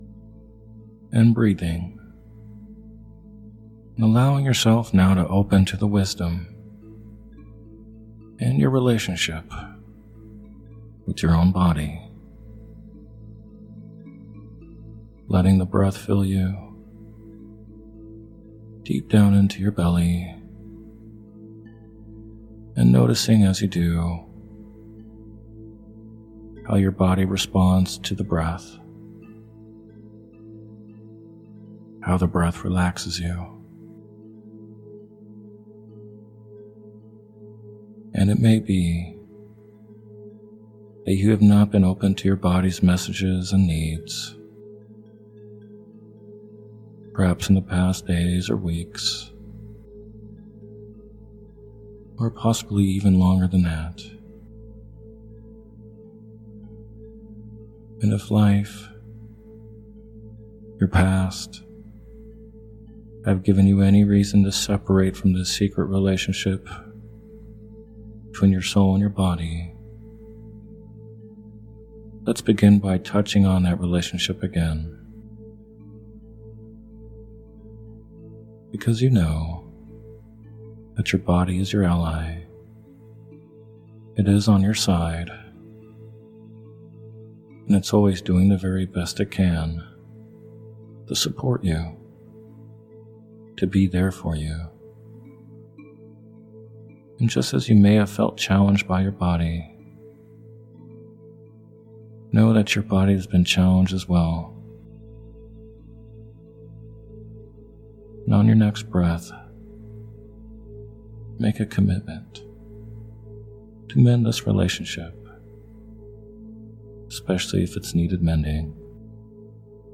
Sleep Hypnosis For Healing While Sleeping – Hypnotic Labs